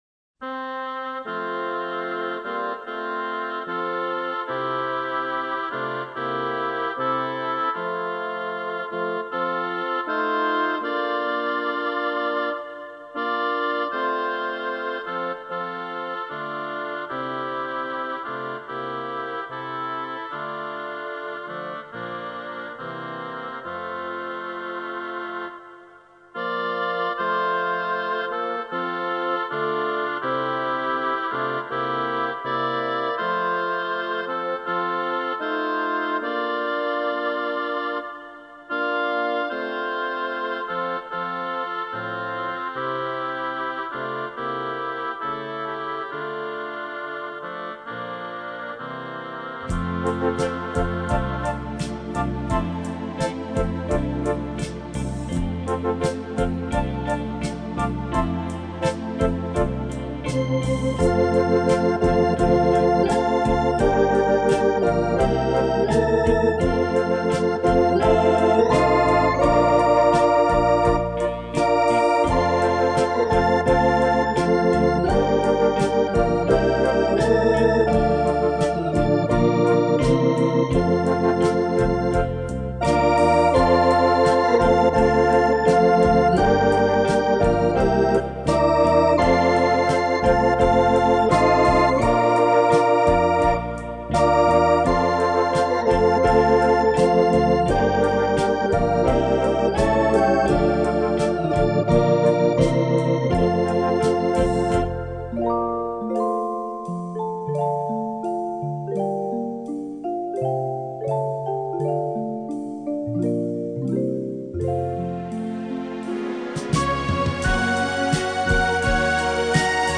这张片是从外国网站下载到的音质水平很低